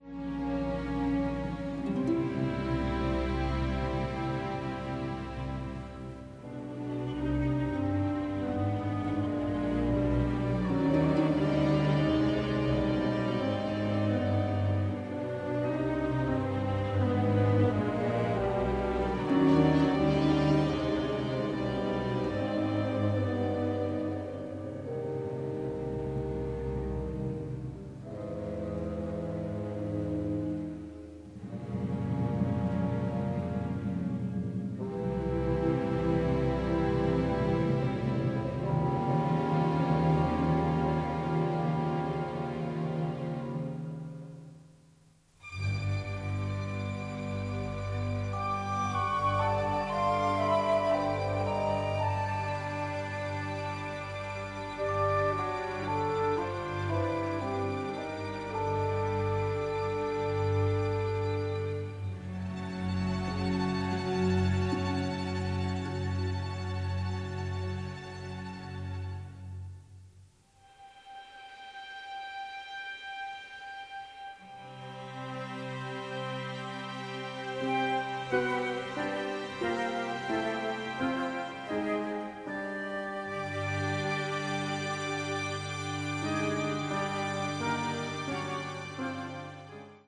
the score is operatic in its power